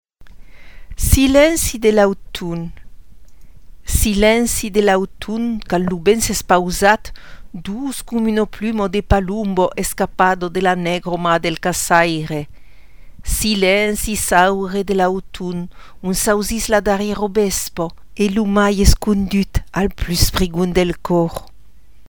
Here’s a short, pretty, Occitan poem to mark the start of Fall.